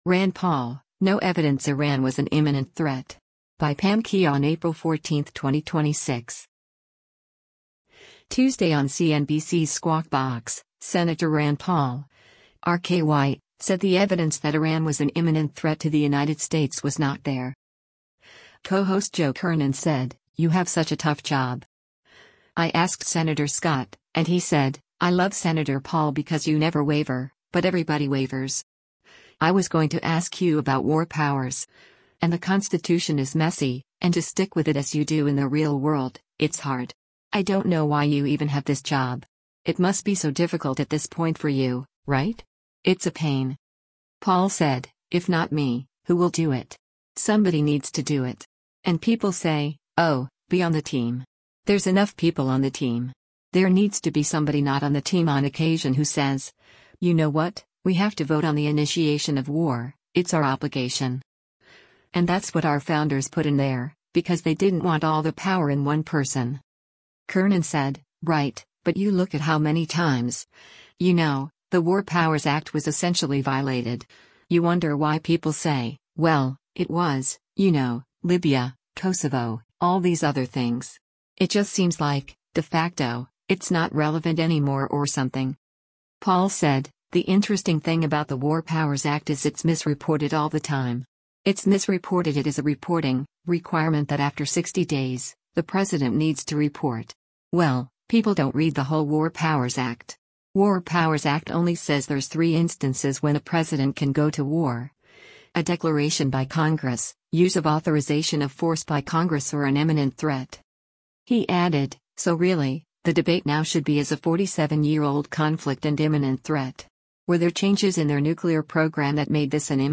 Tuesday on CNBC’s “Squawk Box,” Sen. Rand Paul (R-KY) said the evidence that Iran was an imminent threat to the United States was not there.